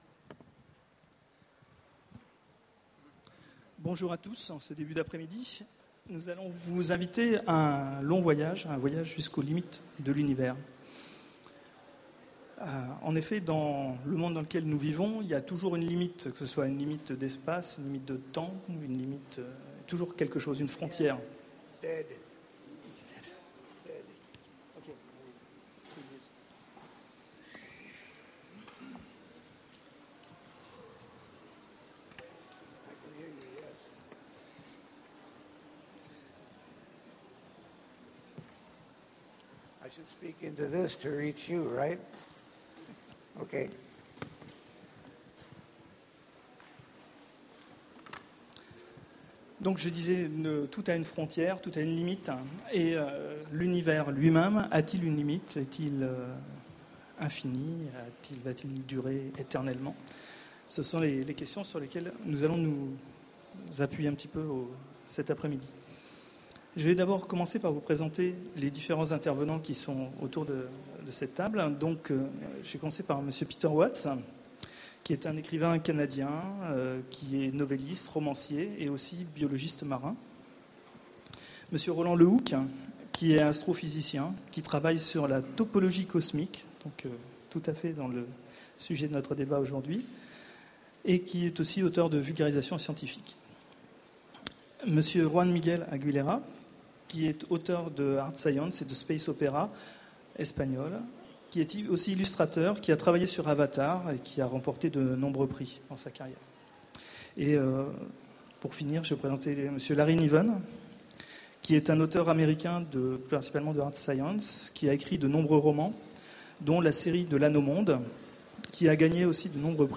Utopiales 2010 : Conférence l'univers a-t-il une limite ?
Voici l'enregistrement de la conférence " L'Univers a-t-il une limite ? " aux Utopiales 2010.